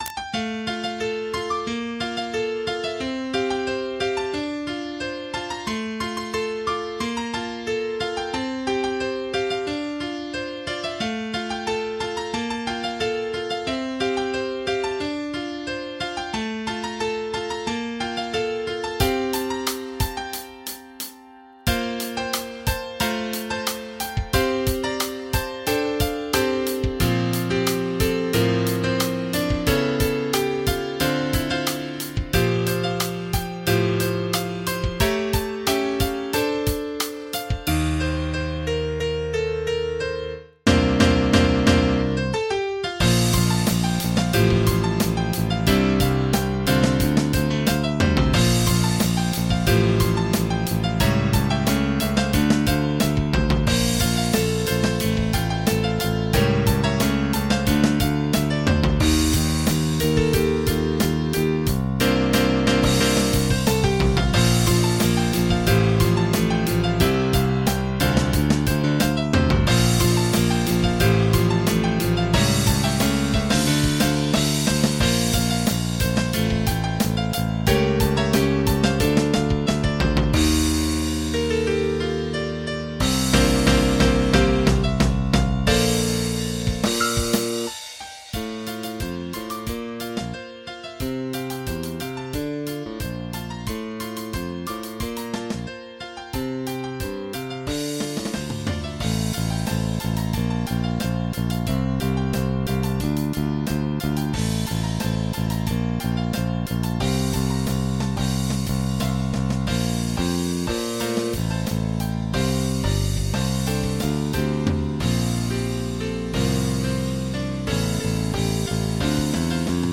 Karaoke Tracks